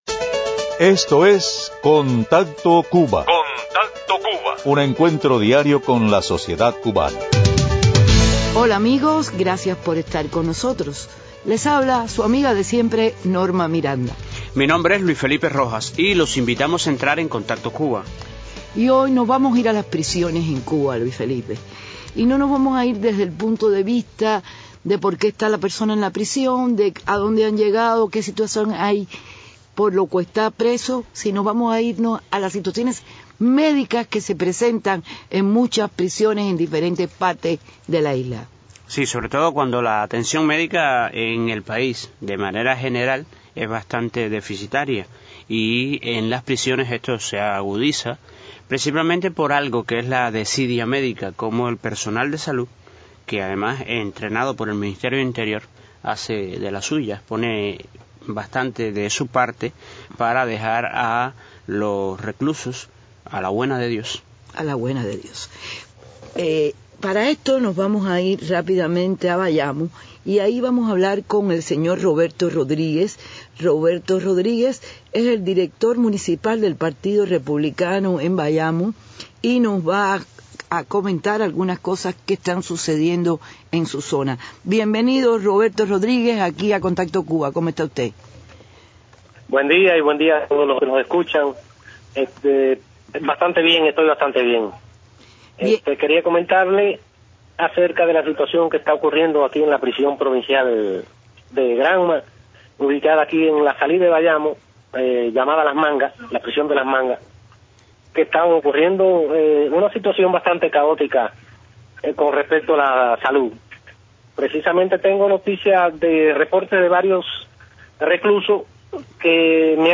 La indolencia y el abuso sitemático en el sistema carcelario cubano es denunciado en estos testimonios de periodistas independientes y activistas de Derechos Humanos.